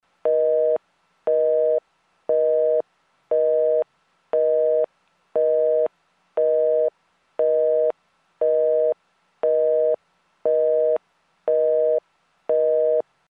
Звуки гудков телефона
На этой странице собраны разнообразные звуки телефонных гудков: от классических сигналов стационарных аппаратов до современных тонов мобильных устройств.